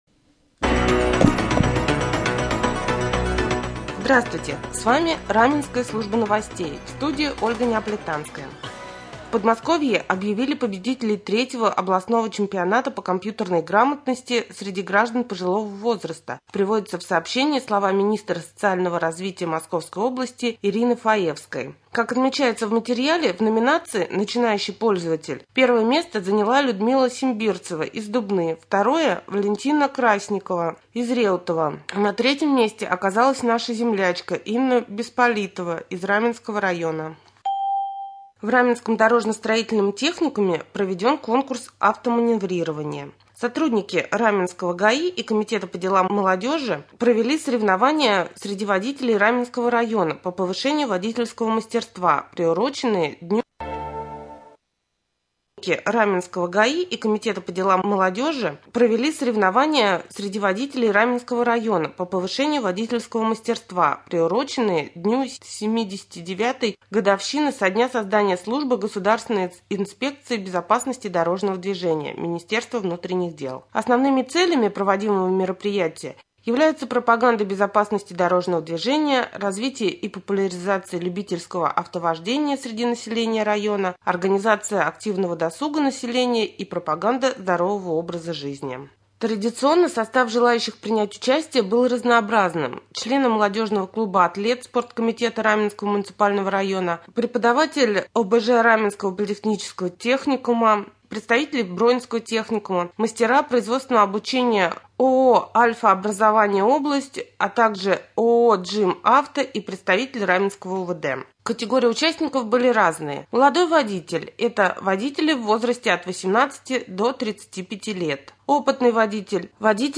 1.Прямой эфир.